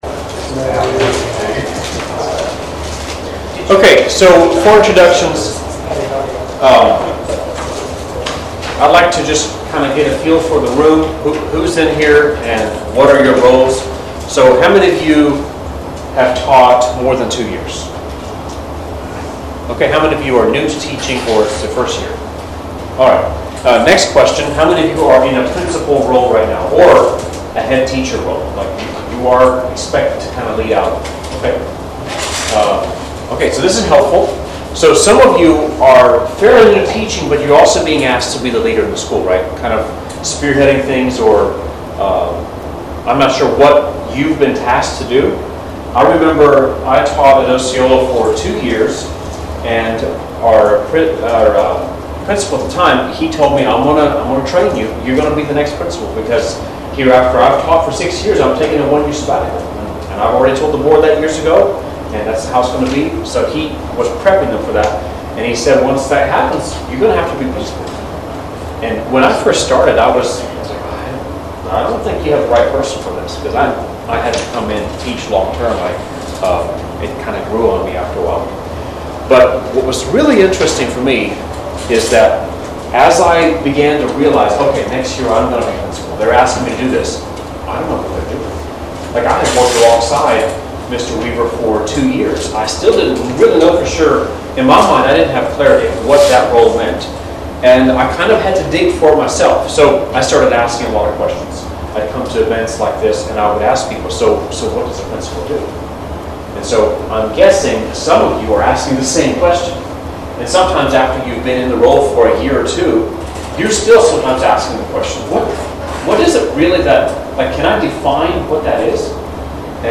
Midwest Teachers Week 2025 Recordings